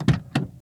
开车门.mp3